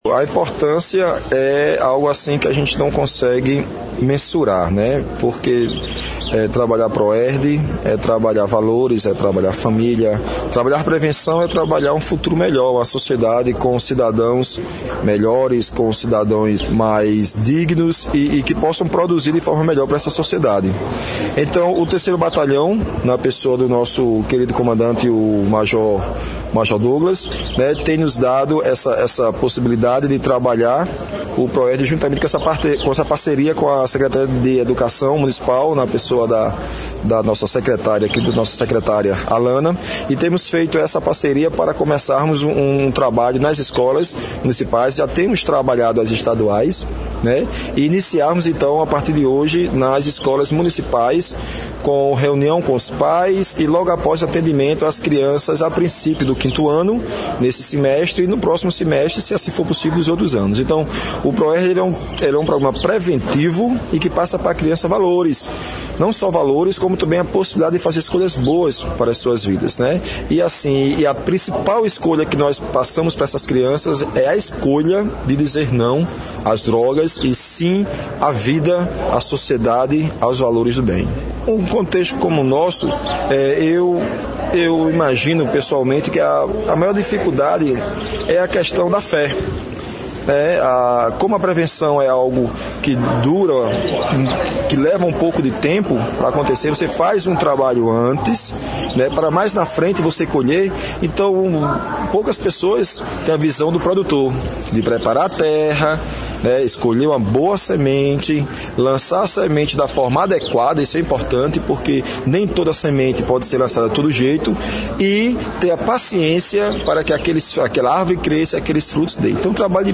Aconteceu na manhã desta quinta-feira (06/04), na sede da Secretaria Municipal de Educação, a solenidade de lançamento do Programa Educacional de Resistência as Drogas – PROERD, numa parceria entre a Polícia Militar do Estado da Paraíba e a Prefeitura de Patos.